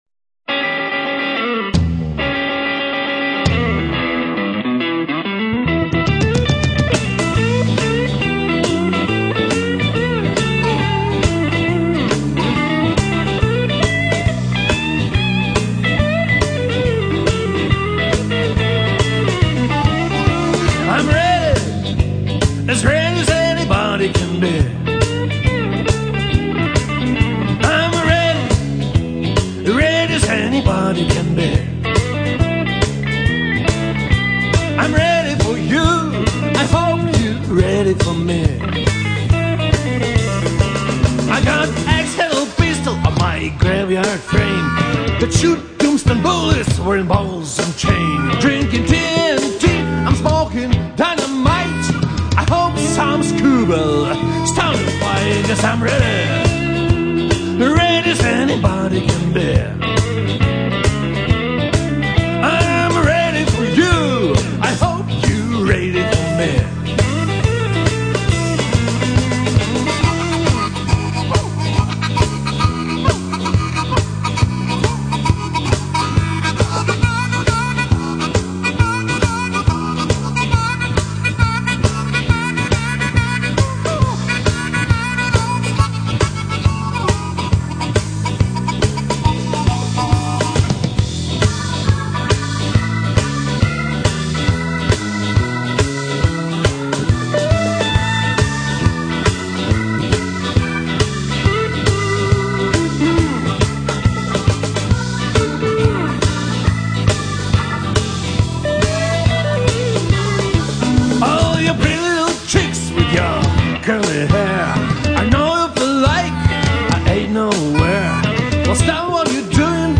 15/01/2011 1-st Alternative Music Club